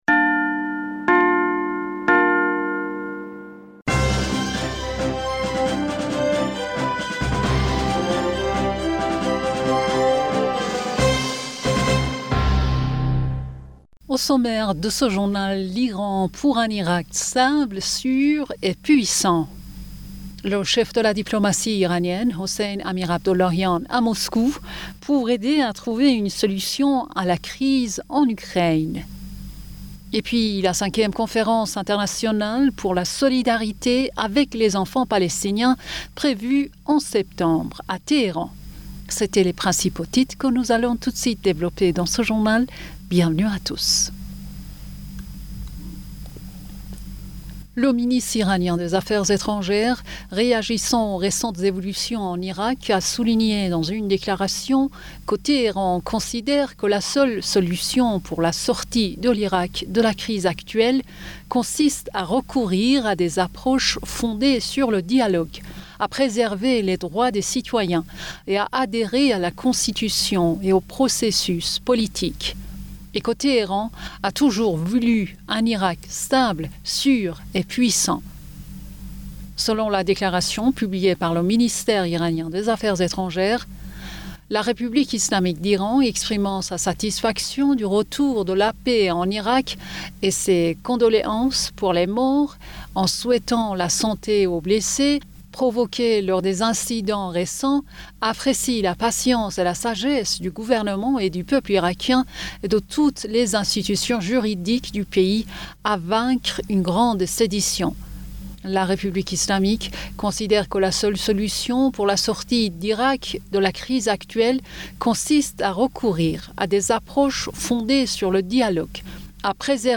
Bulletin d'information Du 31 Aoùt